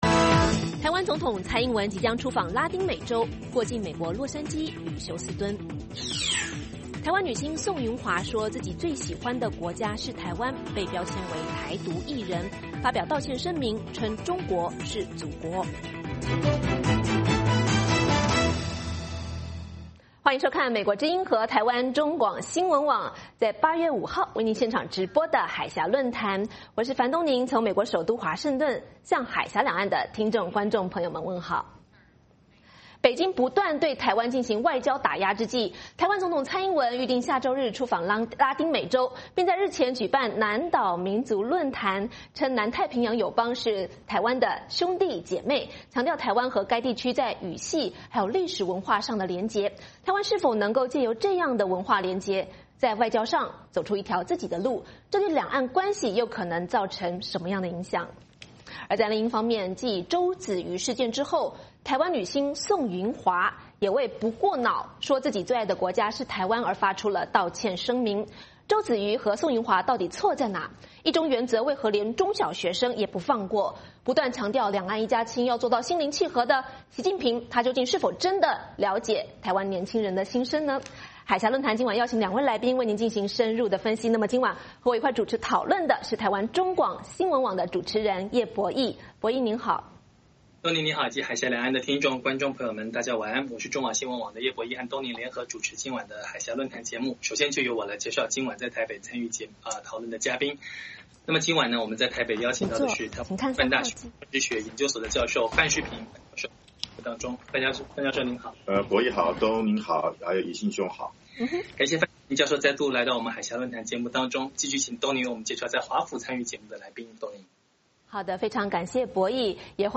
美国之音中文广播于北京时间每周一晚上8－9点重播《焦点对话》节目。《焦点对话》节目追踪国际大事、聚焦时事热点。邀请多位嘉宾对新闻事件进行分析、解读和评论。